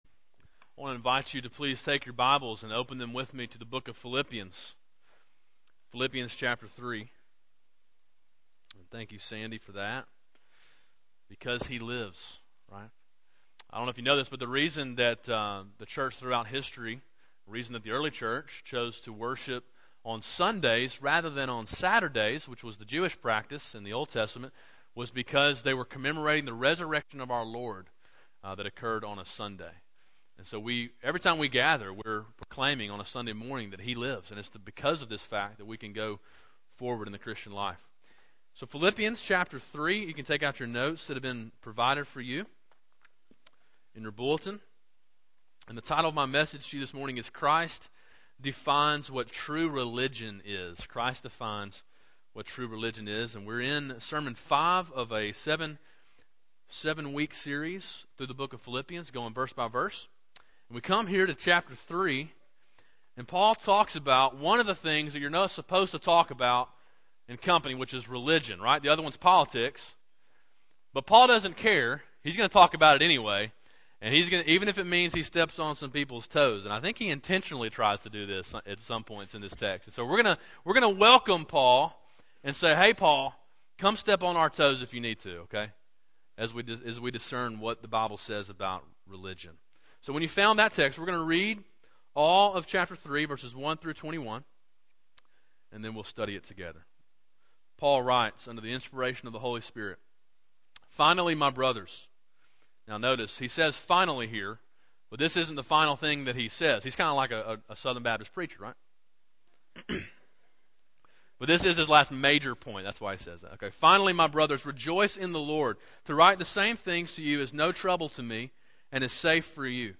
A sermon in the series entitled To Live Is Christ: Verse by Verse through the Book of Philippians. Main point: To live for Christ, we must let him define what true religion is.